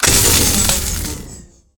overheat.ogg